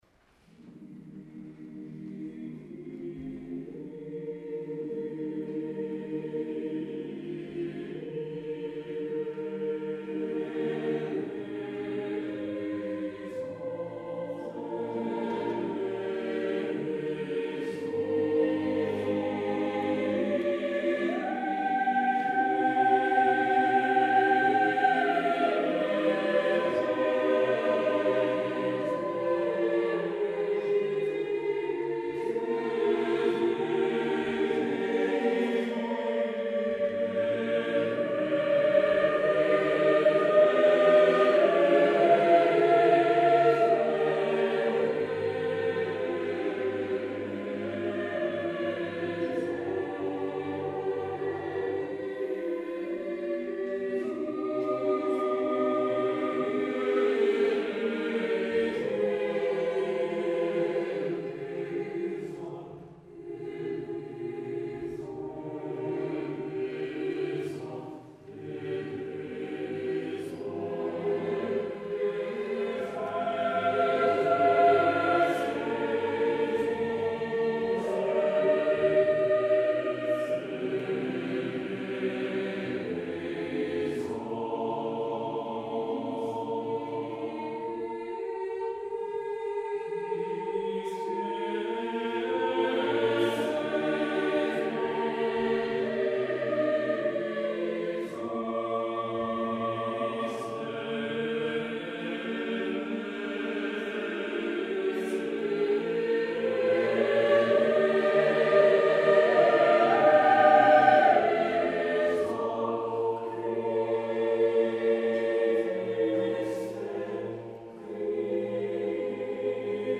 Accompaniment:      None
Music Category:      Choral